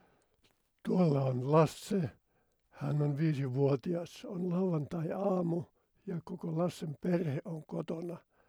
Äännön yhteydessä äänihuulien väliin jäävästä sukkulamaisesta raosta karkaa ilmaa ja äänihuulet värähtelevät epäsymmetrisesti, joka kuullaan käheytenä (kuva 3H).
Kuuntele ääninäyte äänihuuliatrofiasta.